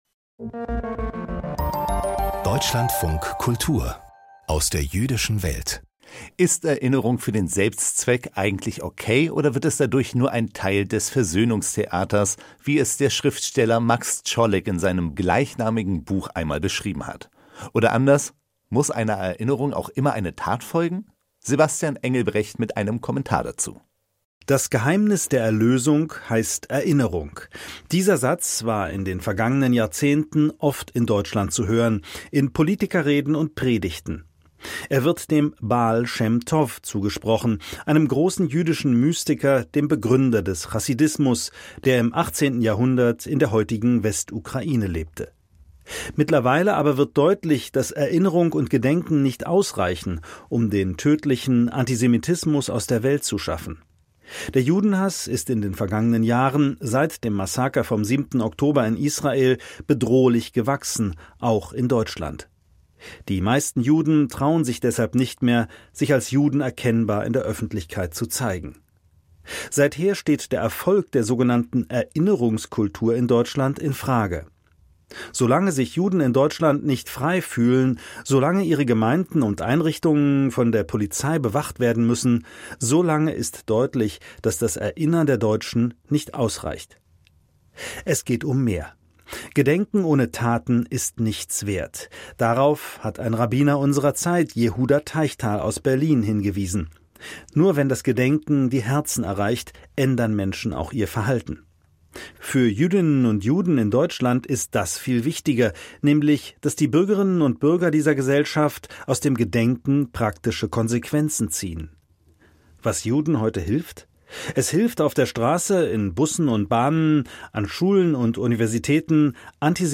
Kommentar: Erinnern allein reicht nicht